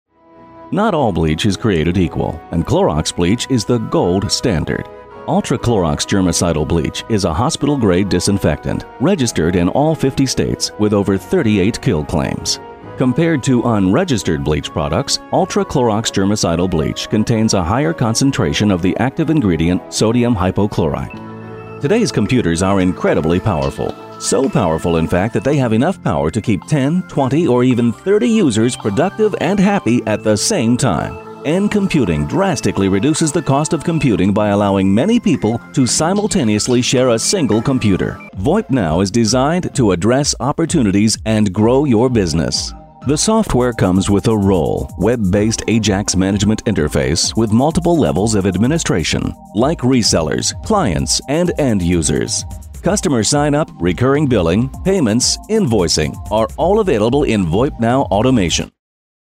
Season voice actor, producer and director as well as character voice specialist.
mid-atlantic
middle west
Sprechprobe: Industrie (Muttersprache):